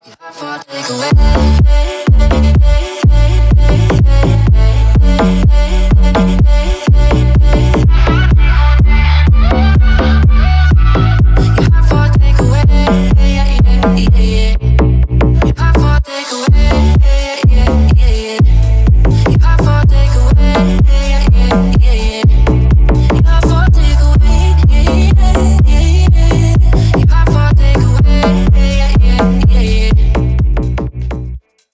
Ремикс # Поп Музыка
тихие